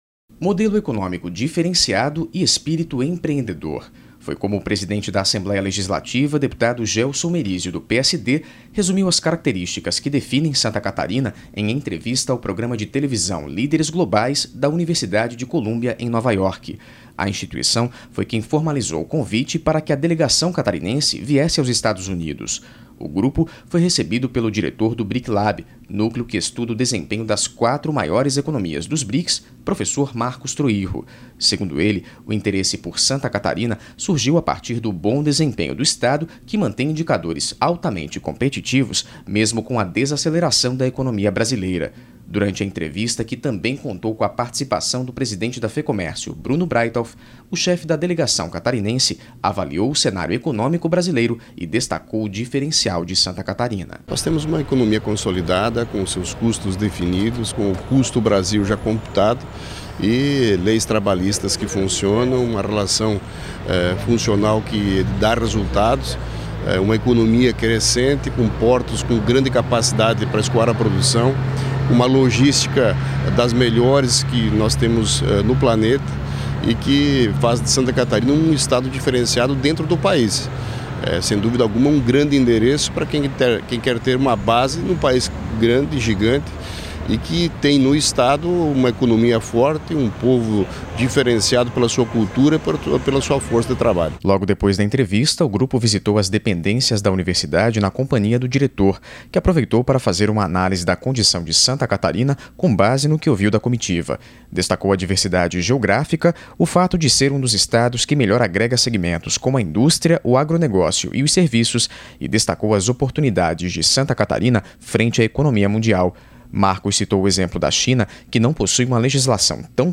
Entrevistas com: deputado Gelson Merisio, presidente da Assembleia Legislativa de Santa Catarina; Marcos Troyjo, diretor BRICLab da Universidade de Columbia; Leonel Pavan, deputado estadual (PSDB)